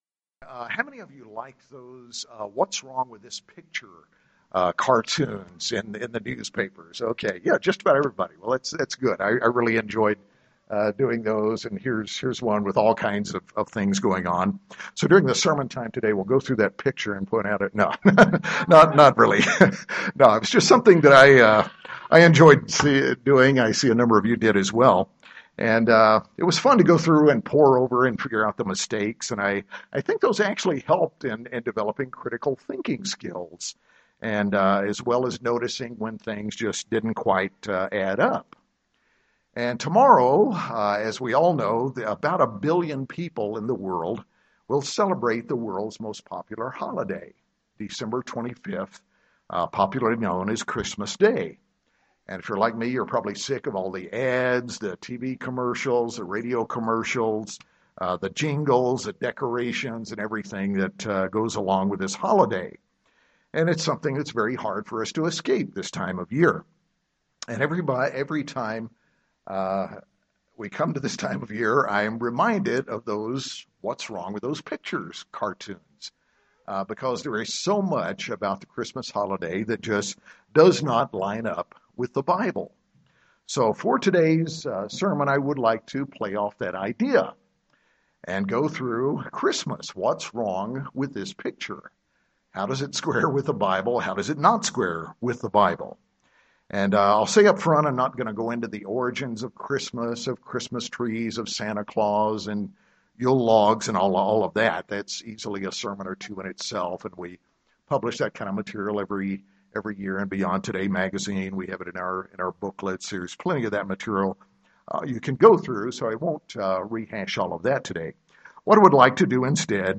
But do its traditions agree with what we actually see in the Bible? In this sermon we examine how many of the common Christmas assumptions and traditions simply aren’t in the Bible or disagree with what the Gospels actually say.